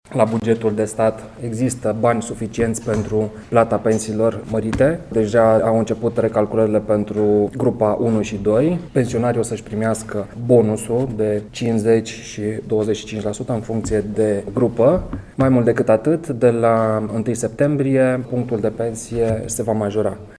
Ministrul Turismului, Bogdan Trif, a dat asigurări, ieri la Sibiu, că există suficienţi bani la buget pentru plata majorărilor determinate de recalcularea pensiilor celor care care au lucrat în grupele I şi a II-a de muncă. El a anunţat, totodată, că punctul de pensie va creşte de la 1 septembrie.